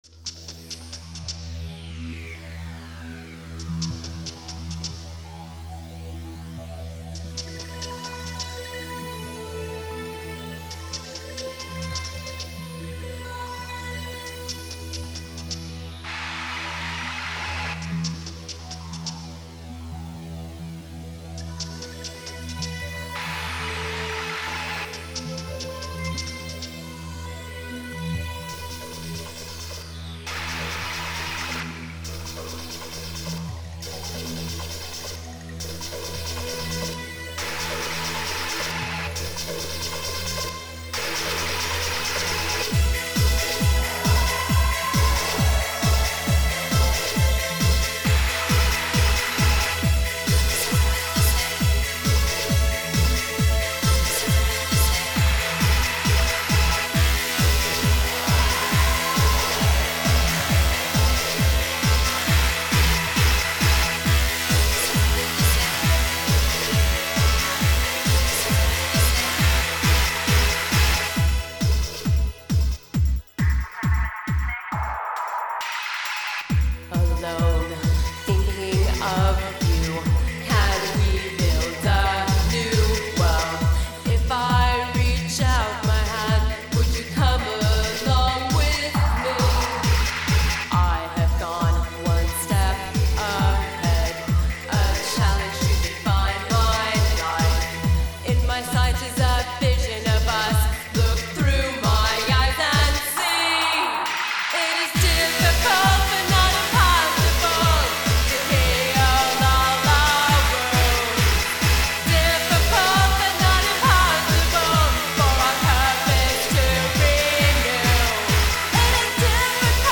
Electronic / Experimental
Club Dance
Techno / Industrial
Synth Pop
Avante Garde